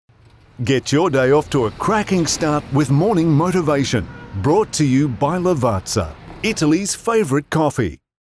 Male
English (Australian)
Adult (30-50), Older Sound (50+)
A warm, genuine, engaging voice that exudes authenticity.
Radio / TV Imaging
Lavazza Promo Spot
0225Lavazza_Mixdown.mp3